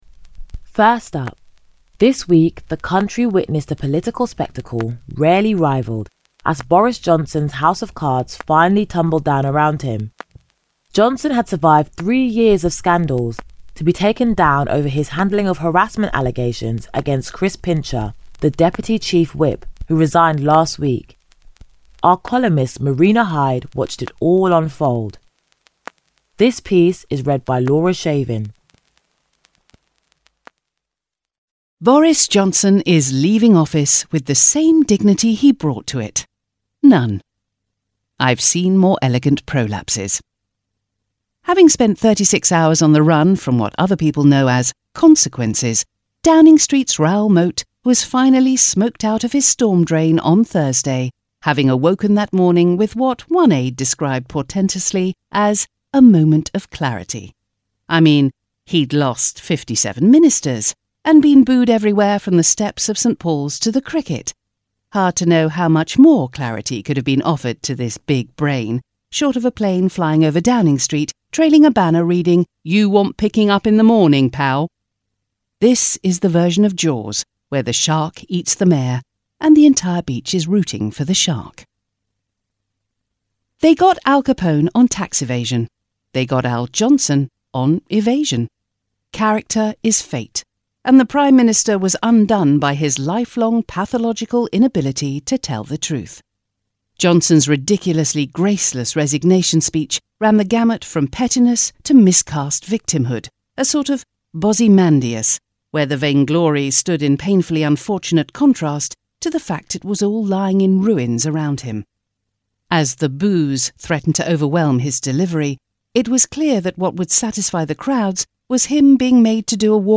Warm, Confident, Persuasive British-English Female Voice Over
NEWSPAPER ARTICLE – Article about Boris Johnson’s resignation for the Guardian Podcast, written by Marina Hyde